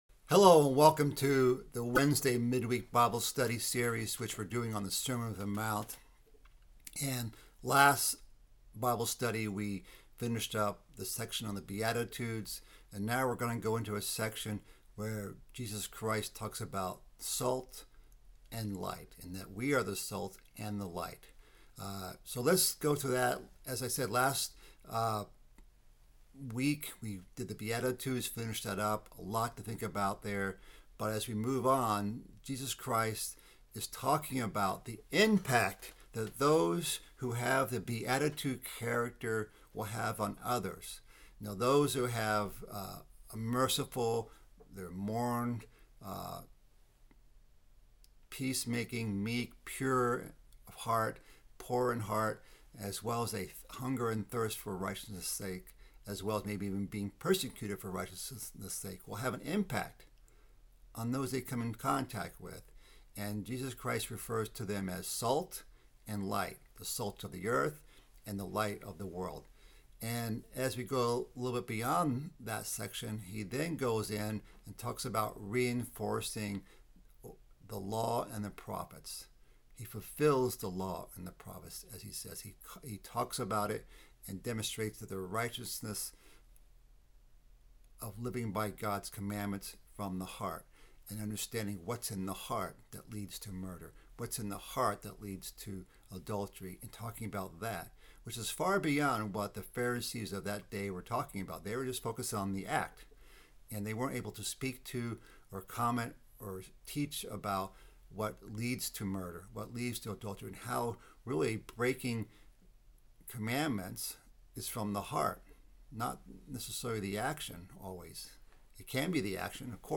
Mid-week Bible study following the sermon on the mount. This week covers the section about being the salt of the earth and the light of the world.